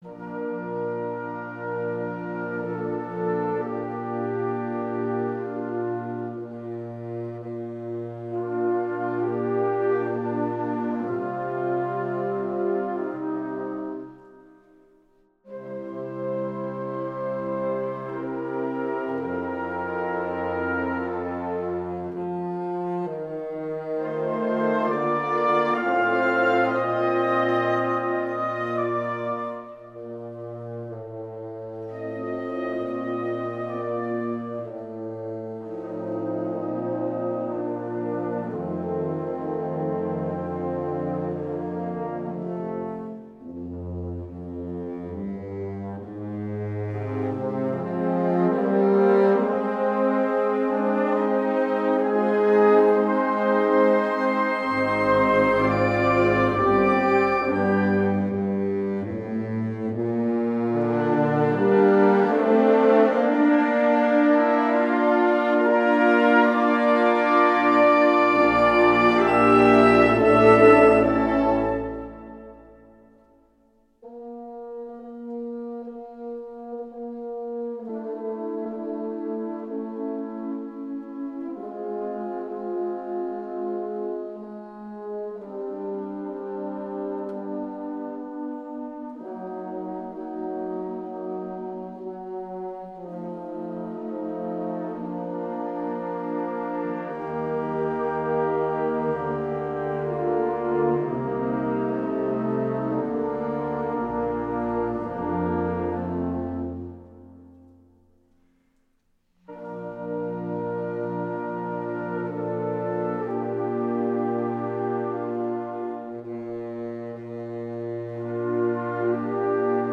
Gattung: Choral
Besetzung: Blasorchester
eine berührende Bearbeitung für Blasorchester